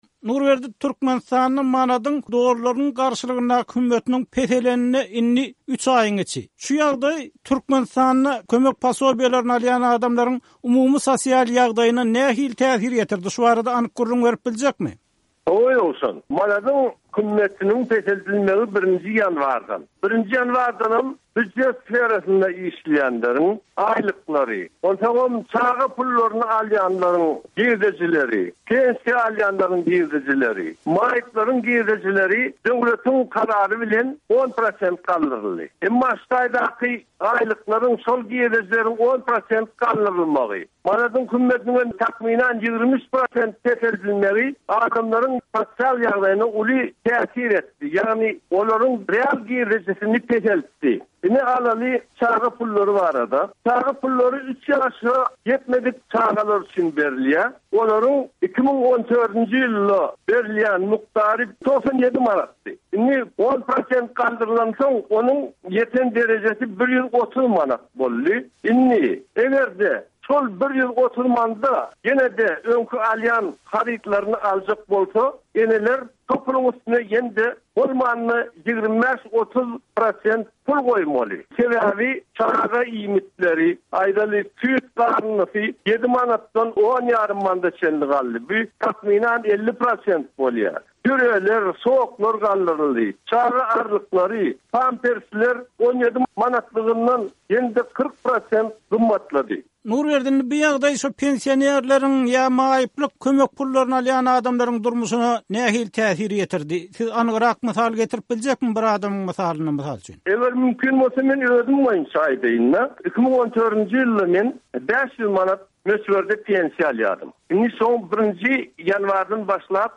söhbetdeş boldy.